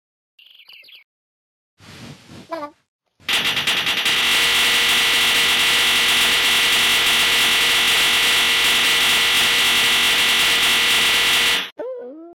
broken sound effect sound effects free download